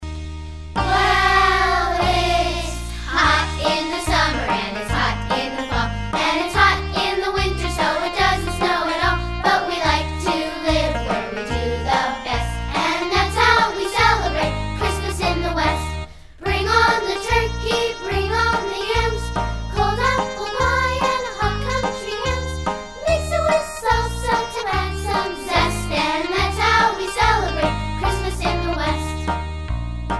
▪ The full-length music track with vocals.
Listen to a sample of this song.